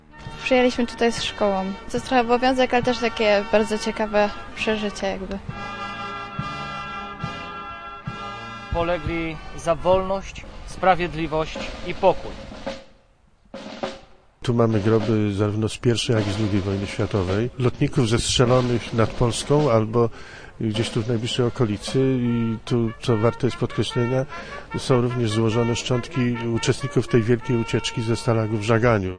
Żołnierze państw Wspólnoty Brytyjskiej, polegli w czasie wojen światowych w okolicach Poznania, zostali upamiętnieni. Na poznańskiej Cytadeli odbyły się uroczystości Dnia Pamięci.
kfeu477tg38iwdt_remembrance_day_cytadella.mp3